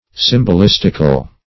Search Result for " symbolistical" : The Collaborative International Dictionary of English v.0.48: Symbolistic \Sym`bol*is"tic\, Symbolistical \Sym`bol*is"tic*al\, a. Characterized by the use of symbols; as, symbolistic poetry.